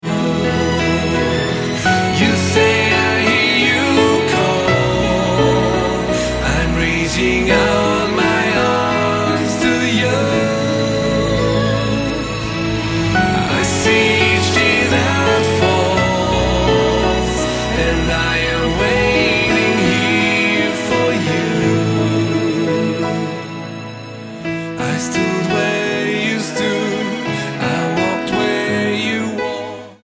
Worship Album